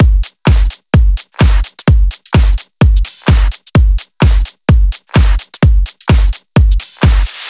VDE 128BPM Renegade Drums 1.wav